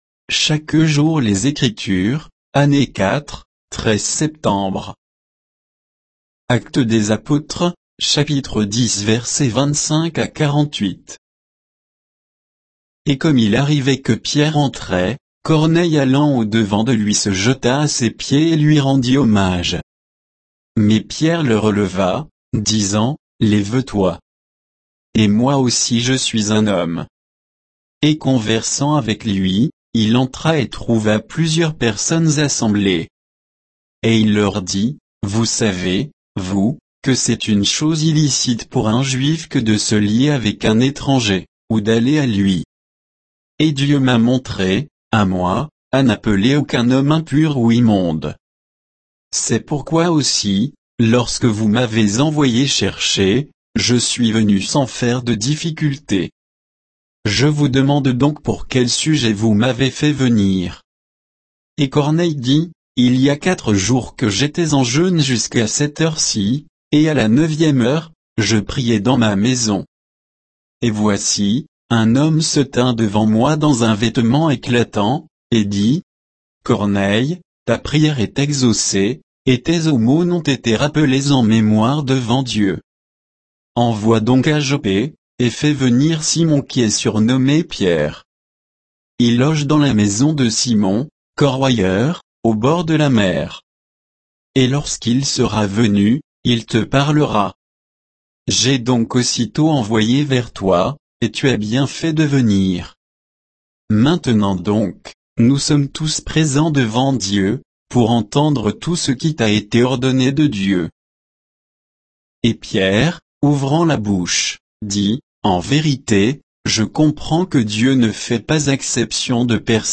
Méditation quoditienne de Chaque jour les Écritures sur Actes 10, 25 à 48